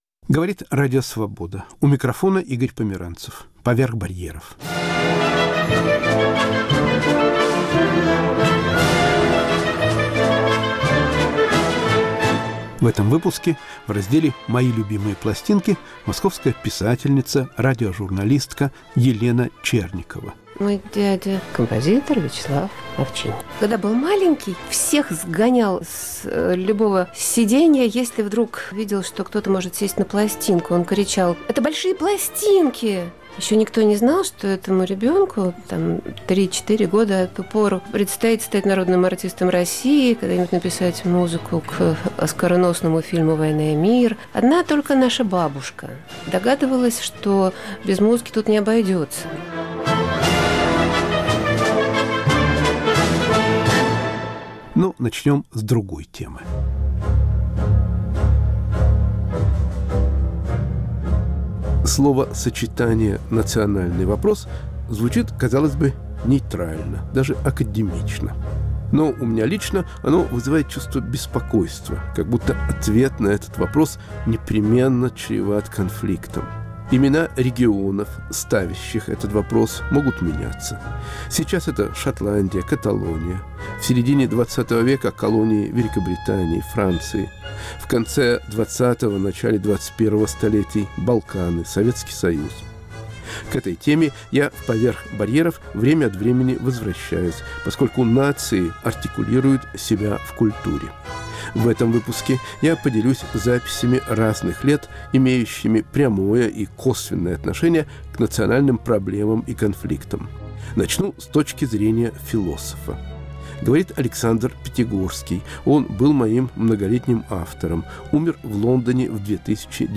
Почему в Англии не охотятся на англофобов? Записи разных лет: А.Пятигорский, А.Стреляный, Д.Рейфилд *** Радиоантология современной русской поэзии.